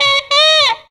UP SAX.wav